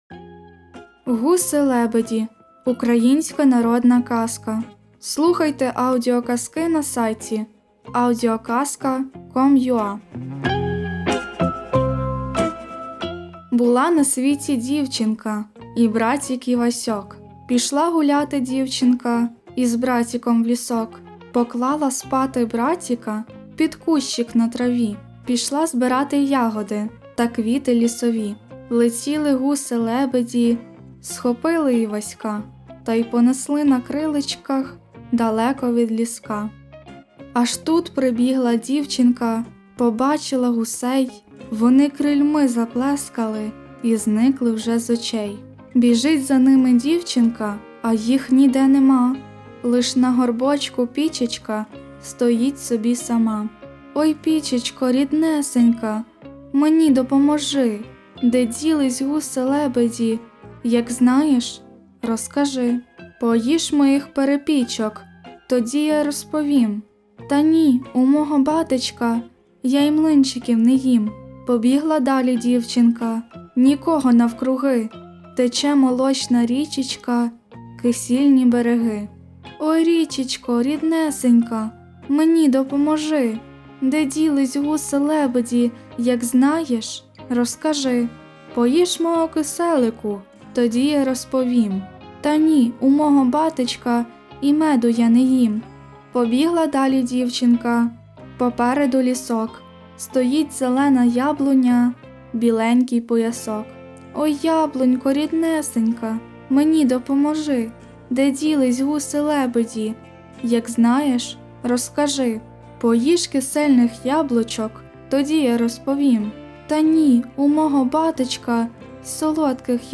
Аудіоказка Гуси-лебеді
Все гарно, тільки дивно чути "братіка" спочатку.